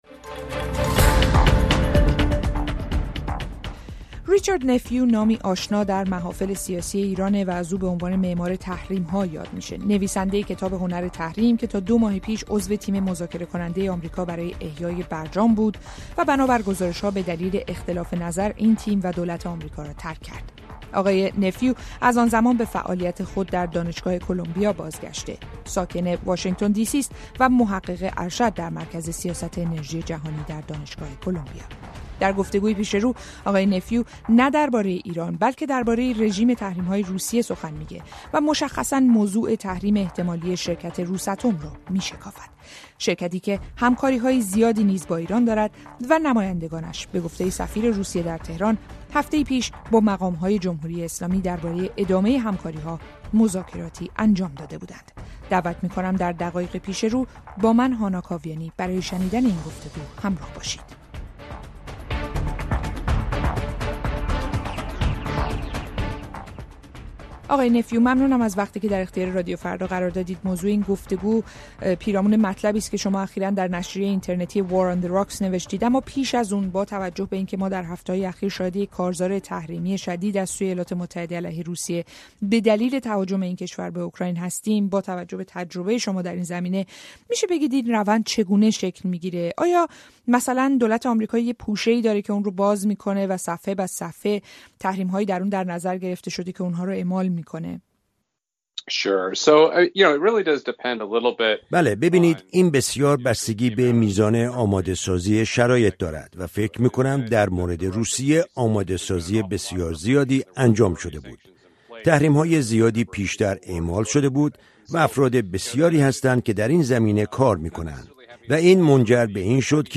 گفت‌وگو با ریچارد نفیو، دیپلمات پیشین آمریکایی و «معمار تحریم‌ها»